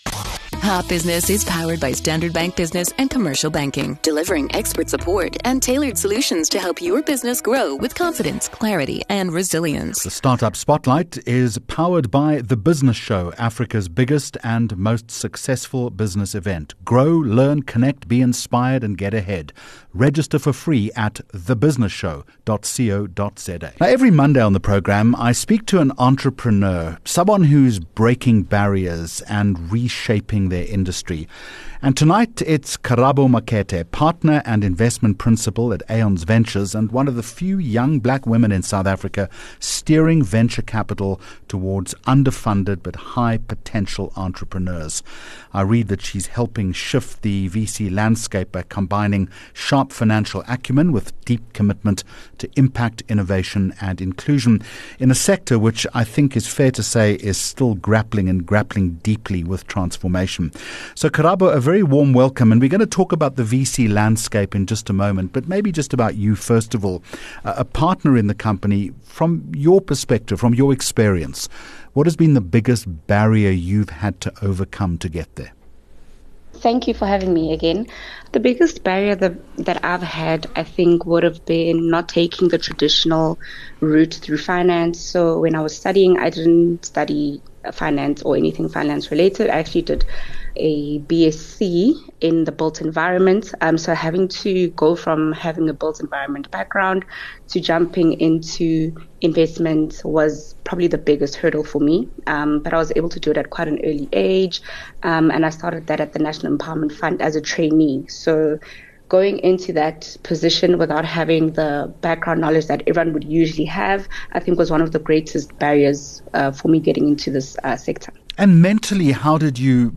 11 Aug Hot Business interview